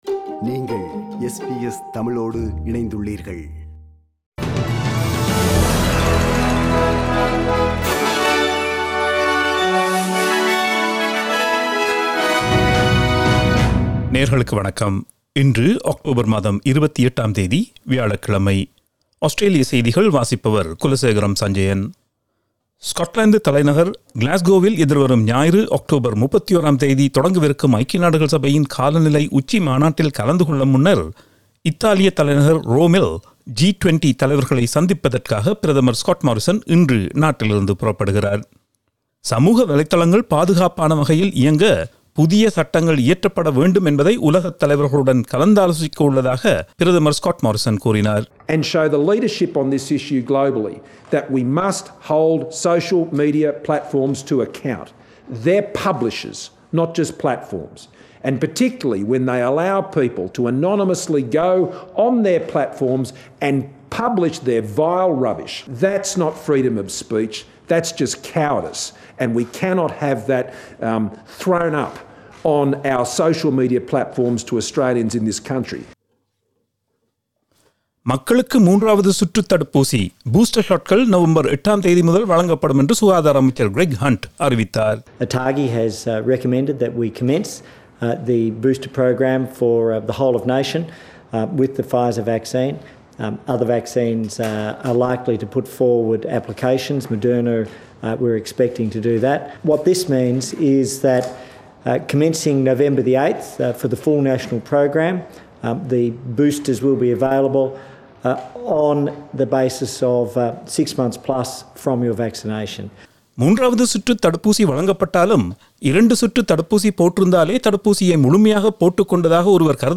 Australian news bulletin for Thursday 28 October 2021.